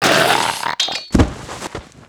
带男声中刀死亡倒地zth070518.wav
通用动作/01人物/02普通动作类/带男声中刀死亡倒地zth070518.wav